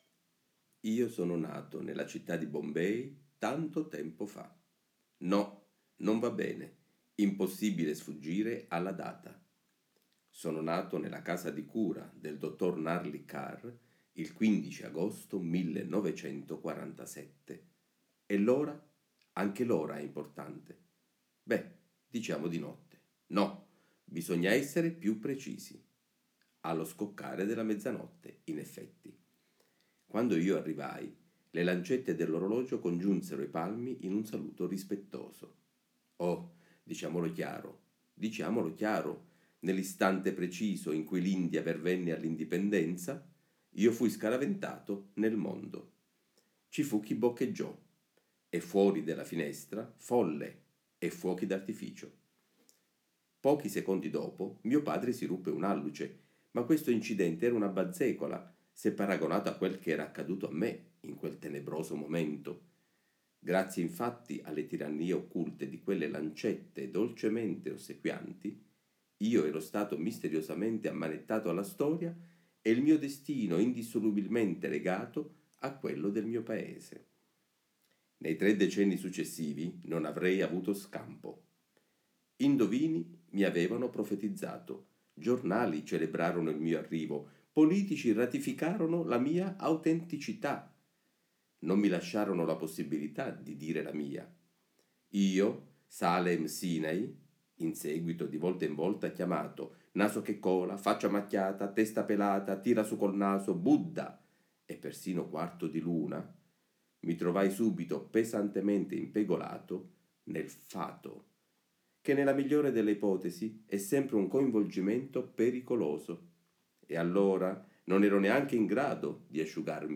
LE AUDIOLETTURE LETTERARIE